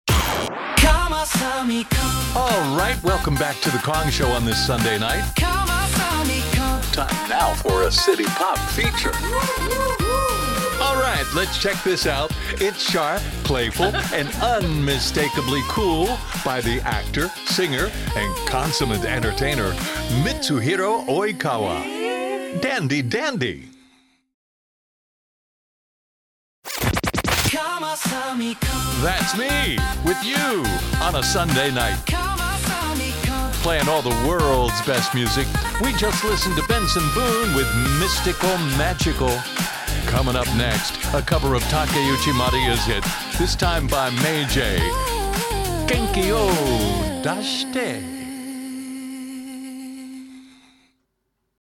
City Pop breaks